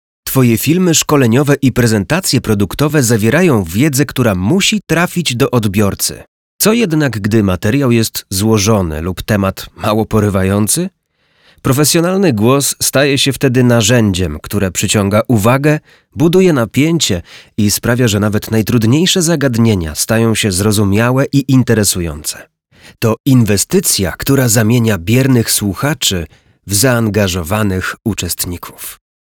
Narracje lektorskie do szkoleń, onboardingów, szkolenia, BPH, prezentacji